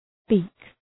Προφορά
{bi:k}